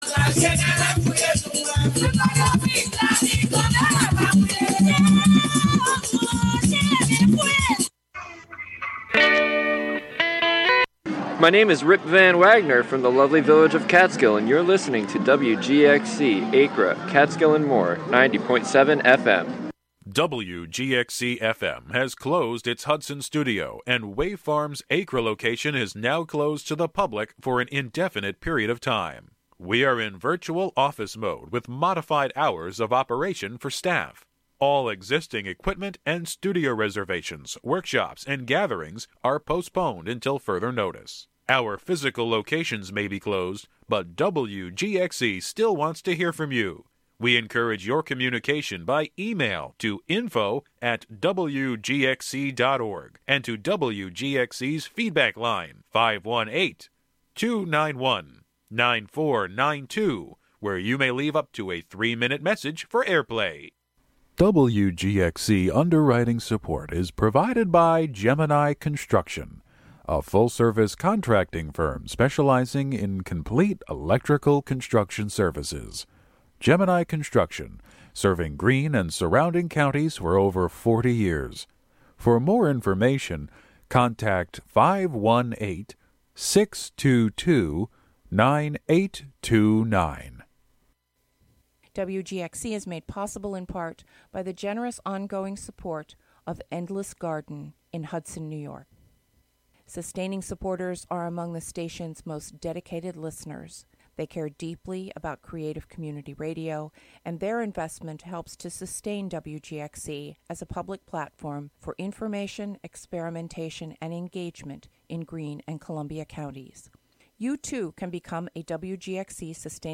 On this show you will hear songs and sounds from a variety of genres as well as from unclassifiable styles of music and experimentation. The show will sometimes feature live performances from near and far and periodically unpredictable guests will join to share music.